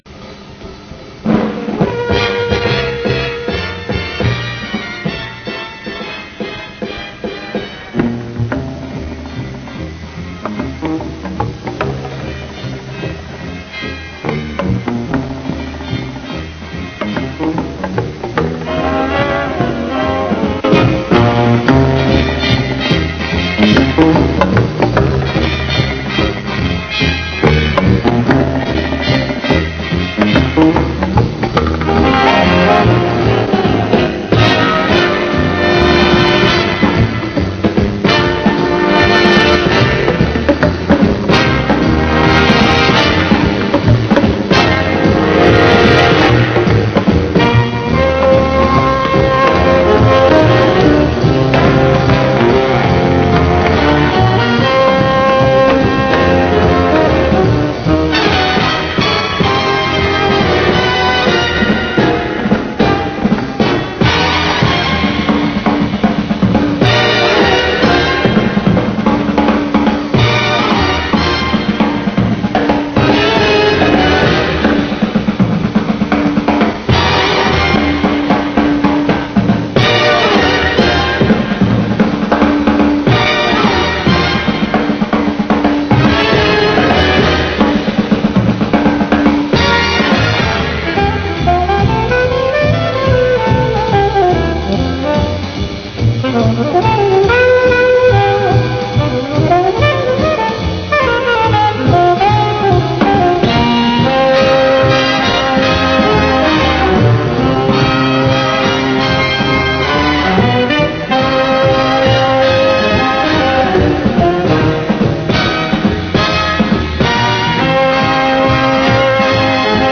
инструментал